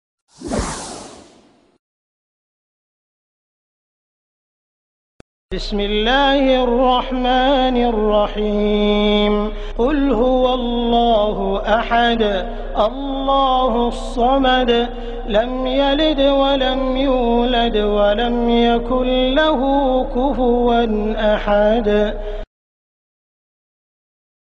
Soothing Recitation of Surah al-Ikhlas
Soothing recitation of Surah al-ikhlas by Sheikh Sudais.